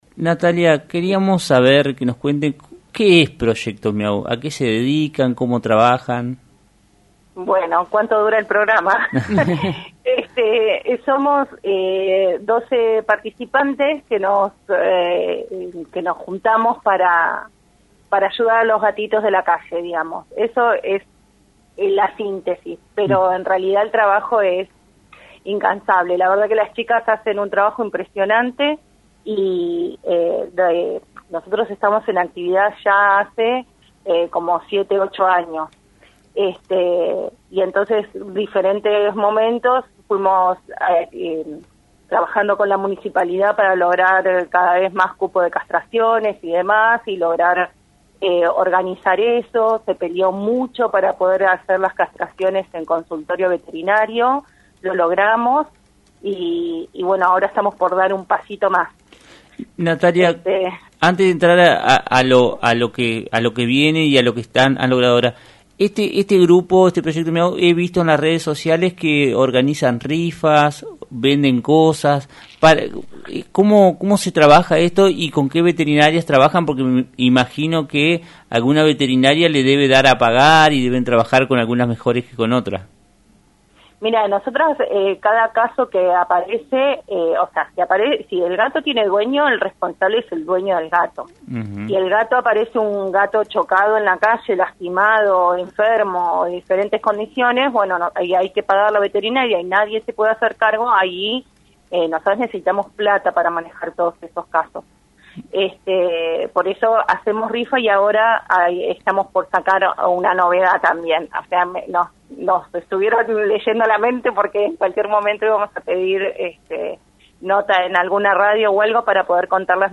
En diálogo con FM 90.3, una de las participantes de esta organización contó que un “michiafiliado” participará de rifas periódicas y de descuentos en aquellos comercios que se adhieran como “Michi-amigos”.